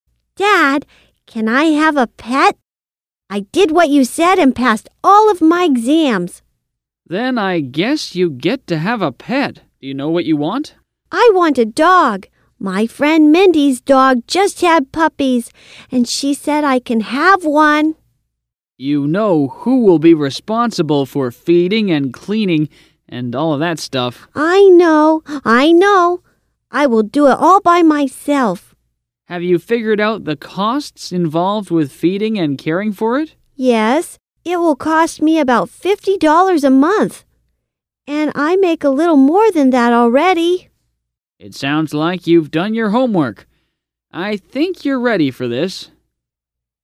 英语情景对话